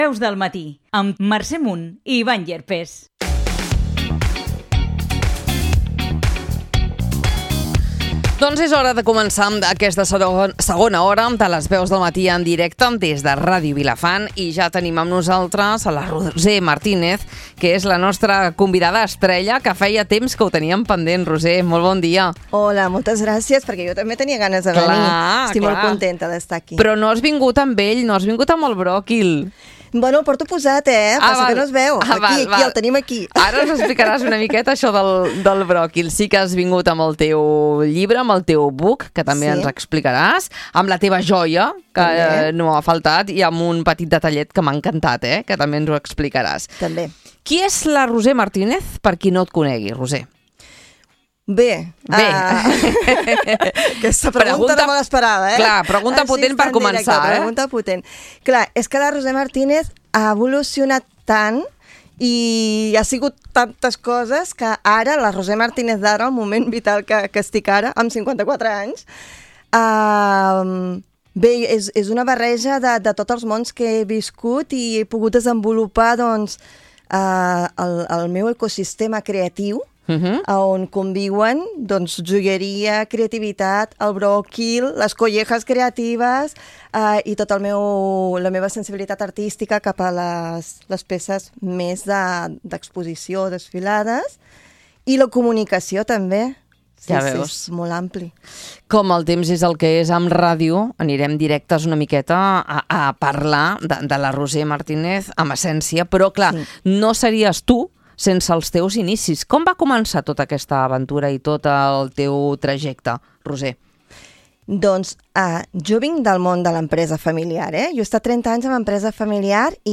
Una conversa per conèixer millor la seva trajectòria, les inquietuds que la mouen i tot el que està desenvolupant en aquests moments.